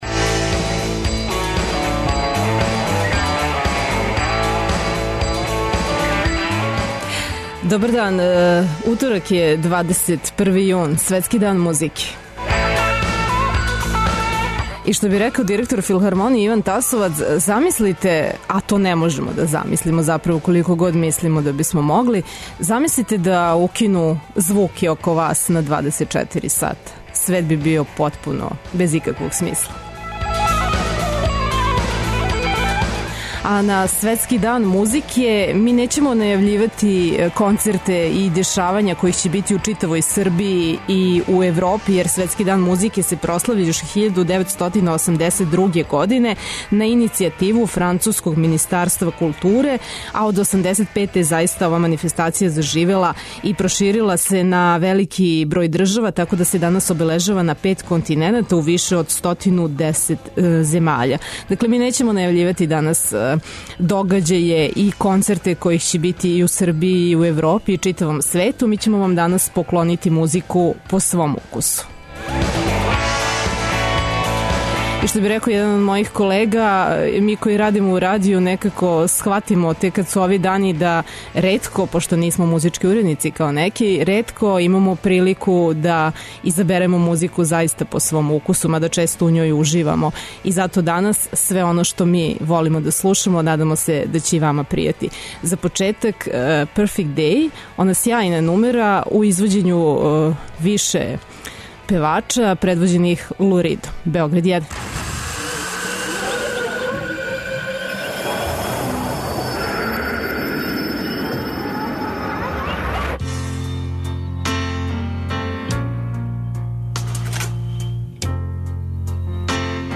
На Светски дан музике, поклањамо вам музику по избору уредника, новинара и продуцената Радио Београда 1.